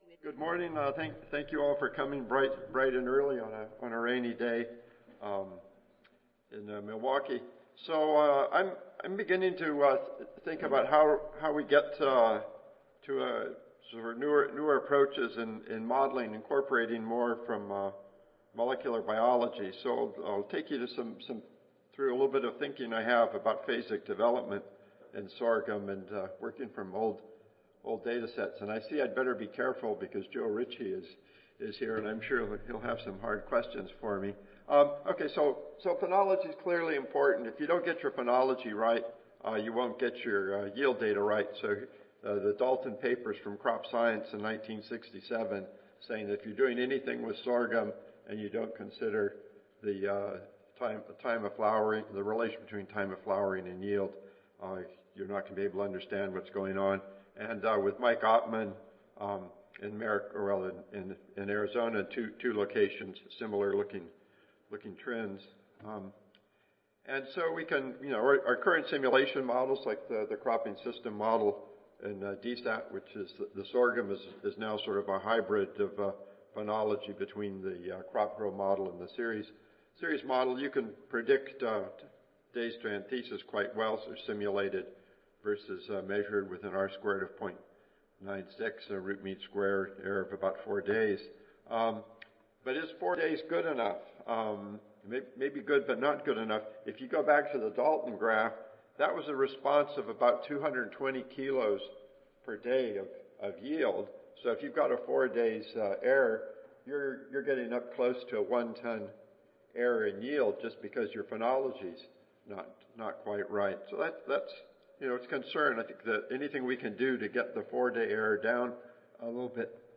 Session: Agroclimatology and Agronomic Modeling (ASA, CSSA and SSSA International Annual Meetings (2015))
Michigan State University Audio File Recorded Presentation